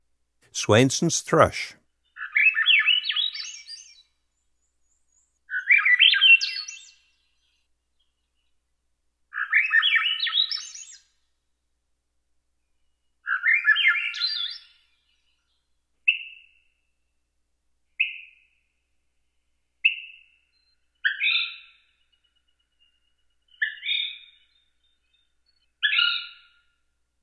Since then his lovely, flute-like, upwardly spiraling songs have graced my yard every day!
Swainson's Thrush
The beautiful ascending, flute-like song seemed to occur effortlessly all through the day.
swainsons-thrush-trimmed.m4a